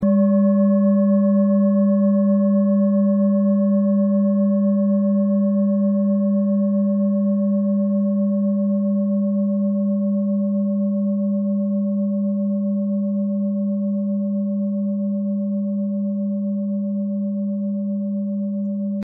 Klangschale Bengalen Nr.27
Sie ist neu und wurde gezielt nach altem 7-Metalle-Rezept in Handarbeit gezogen und gehämmert.
(Ermittelt mit dem Filzklöppel)
klangschale-ladakh-27.mp3